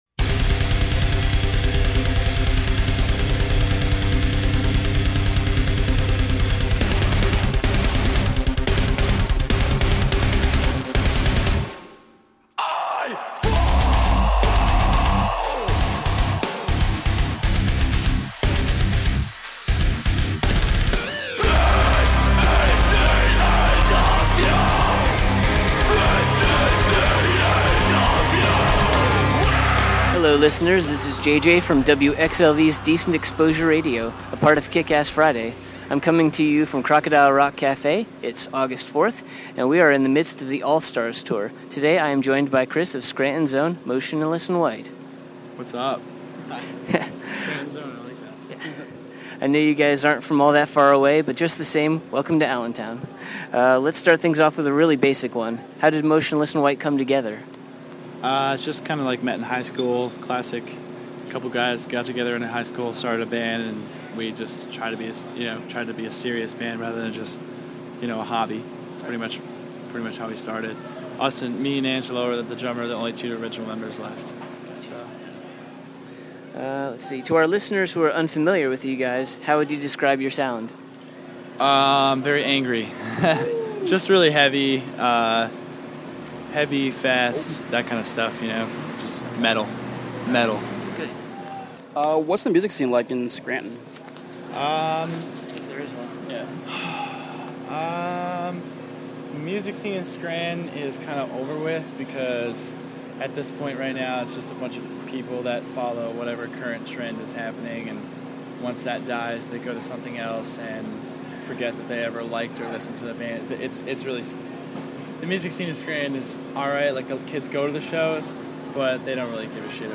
Exclusive: Motionless In White Interview
10-interview-motionless-in-white.mp3